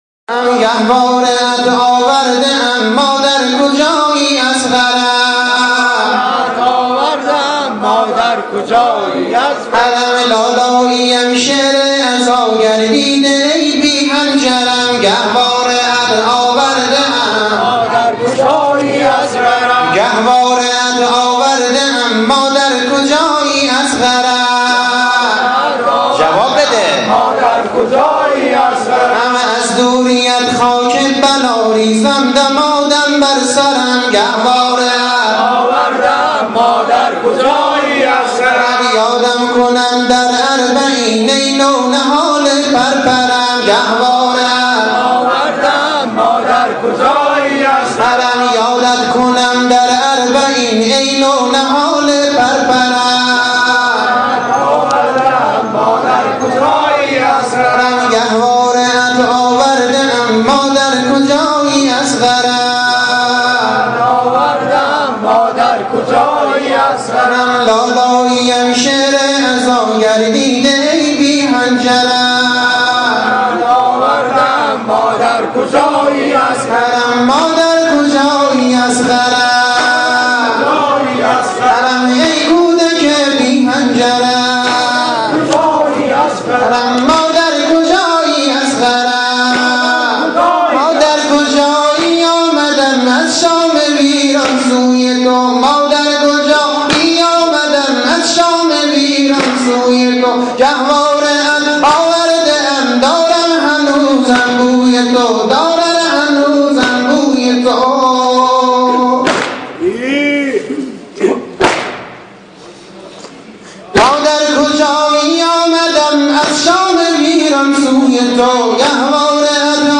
متن و سبک نوحه اربعین -( گهـواره ات آورده ام مادر کجایی اصغرم )